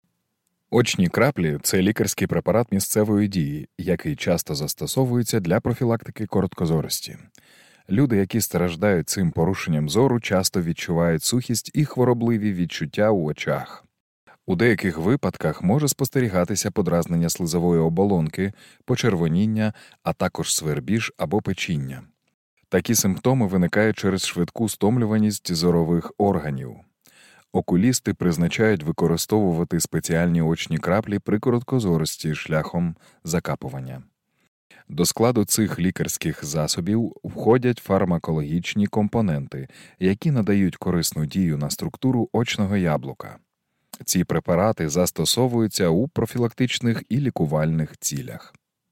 Чоловіча
Проф. актор театру на кіно, актор дубляж, диктор.
Зміна голосу, акцентів.